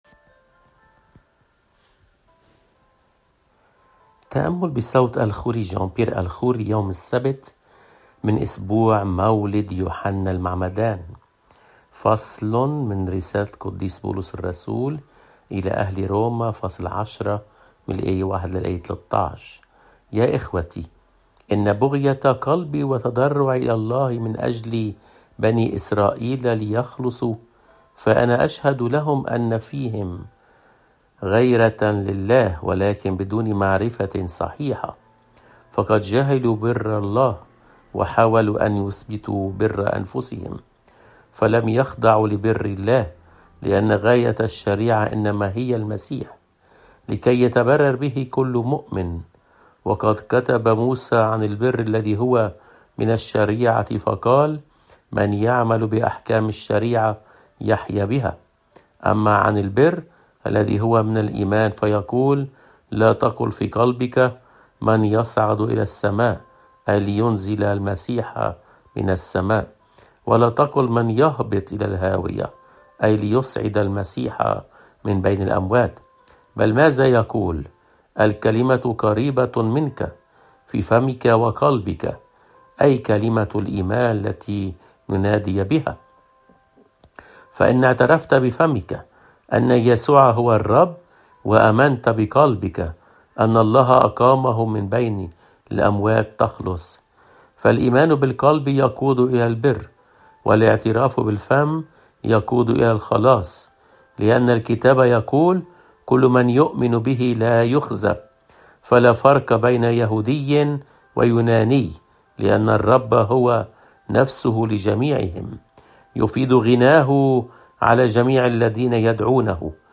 قراءات روحيّة صوتيّة - Yasou3na